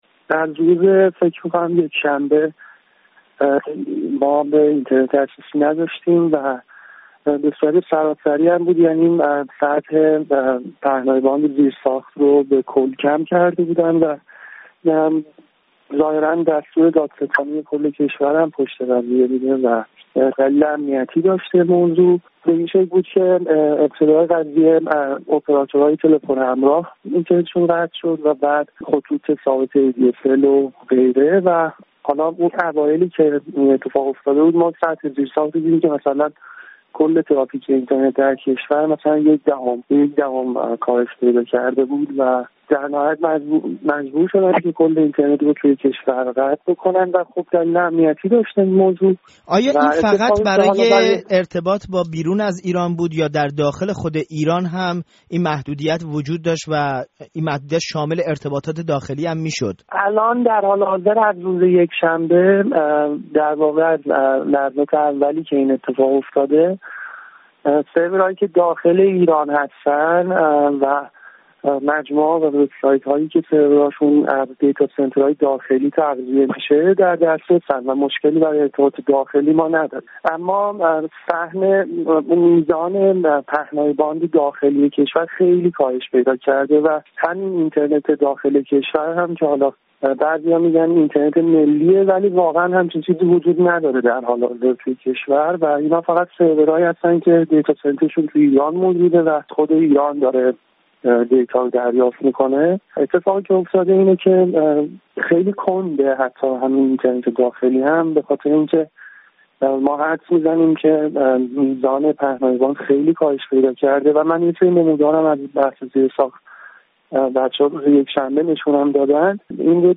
گفت‌وگو با یک کارشناس درباره وضعیت اینترنت در ایران